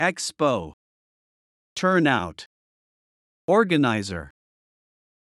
音声を再生し、強勢のある母音（＝大きな赤文字）を意識しながら次の手順で練習しましょう。
expo /ˈɛkspoʊ/（名）博覧会、展示会
turnout /ˈtɜːrnaʊt/（名）来場者数、出席者数
organizer /ˈɔːrɡənaɪzər/（名）主催者、企画者